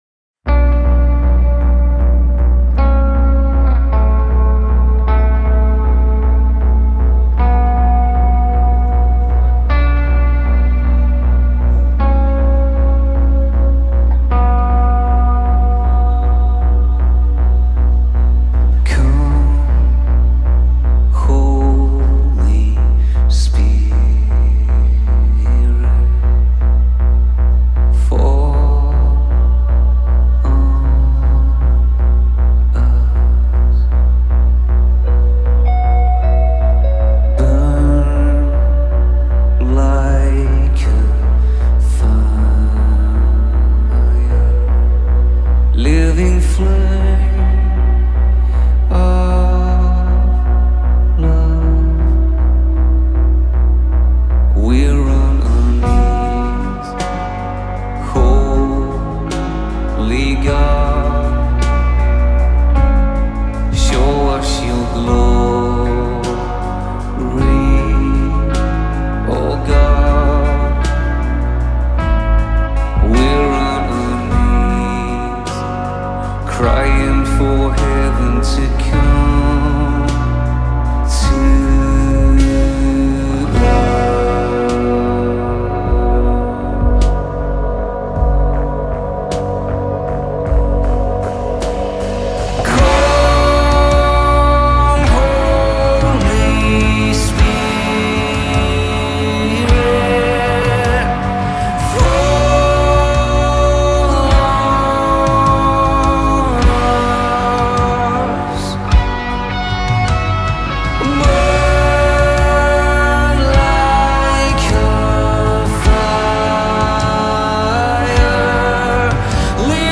8399 просмотров 8343 прослушивания 568 скачиваний BPM: 156